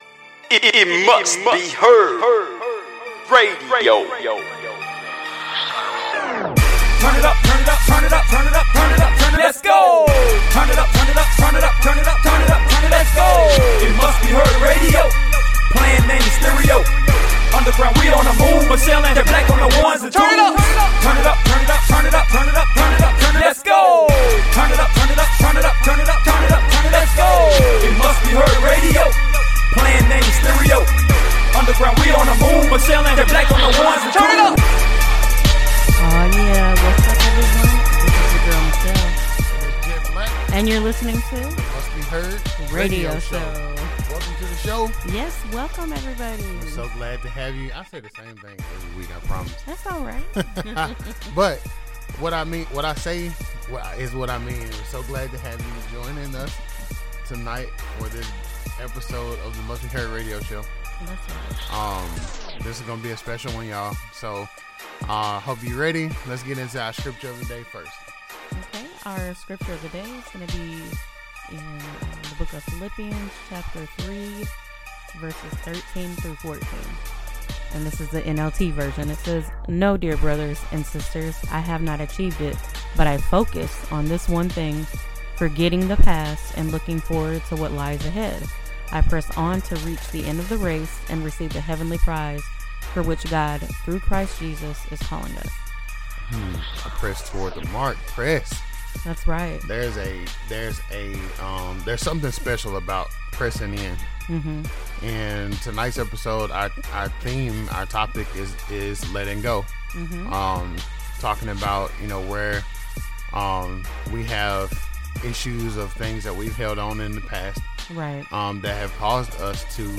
The Must Be Heard Radio Show focuses on playing music from today's Independent Christian/ Gospel artists.